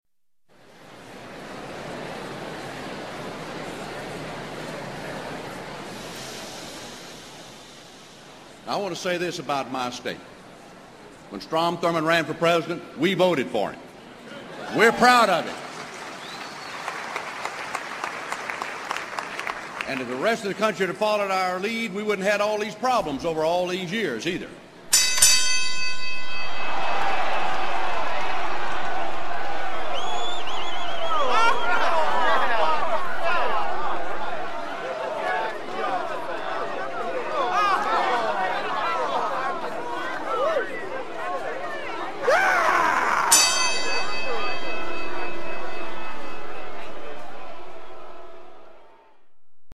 In the sound clip, users first hear the crowd hushed and then once the politician speaks, a bell rings and the user can punch away.
sample, the sequence of sounds is the crowd being hushed, Trent Lott speaking and the round begins. If you listen carefully, you'll hear the crowd react to particularly forceful punches and the Howard Dean scream to signify the end of that round.